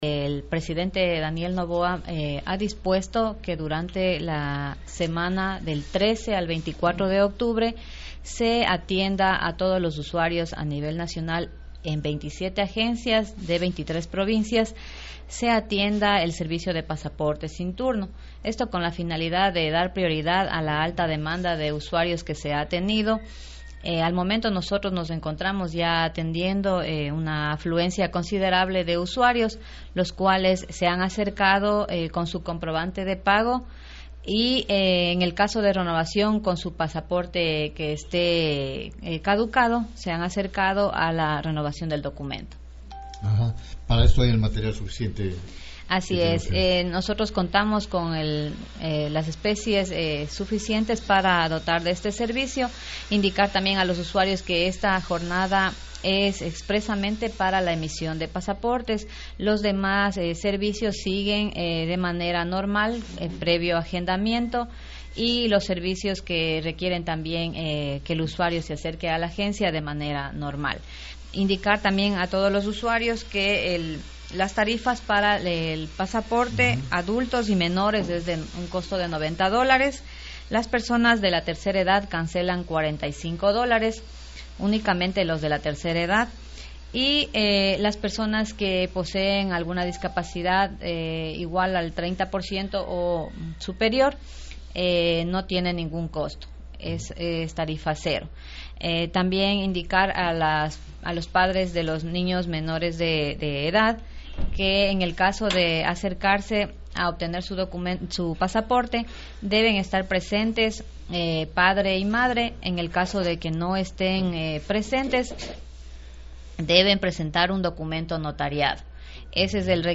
ENTREVISTA: Janeth Núñez, directora Registro Civil-Pastaza.
JANET-PASAPORTES.mp3